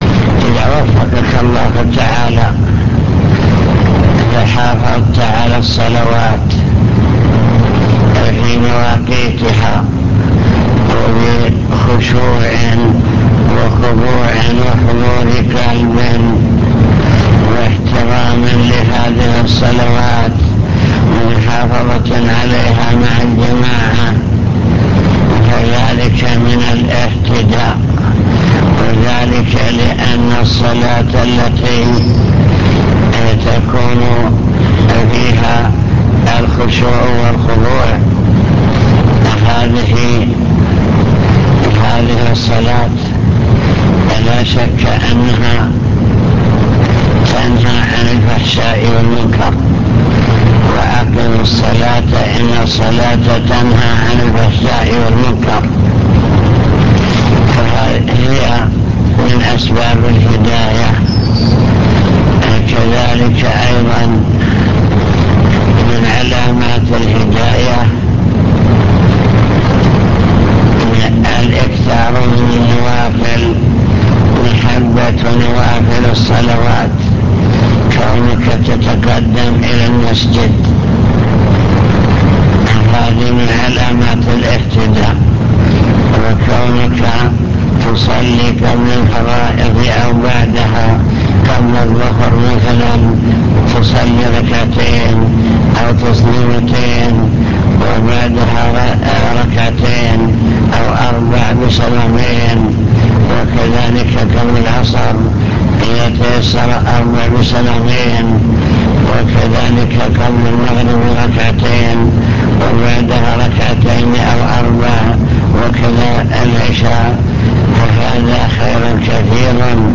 المكتبة الصوتية  تسجيلات - محاضرات ودروس  توجيهات سلاح الحدود